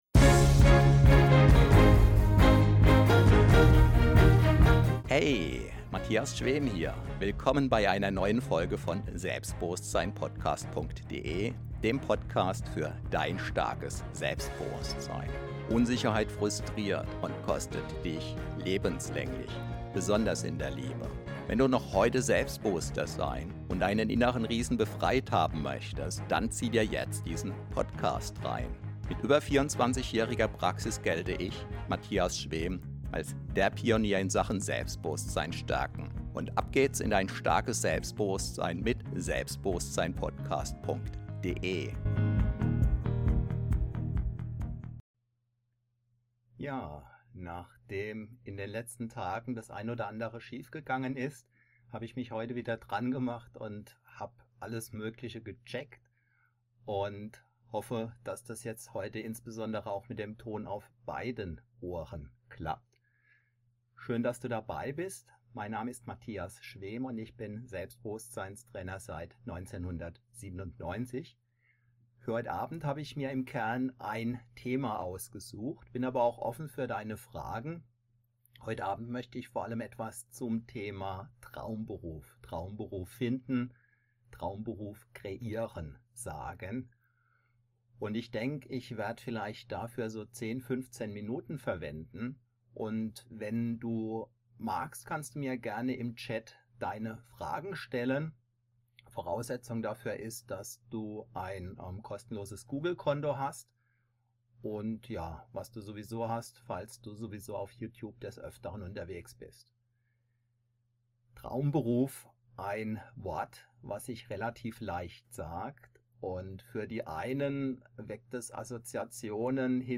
LiveStream/Realtalk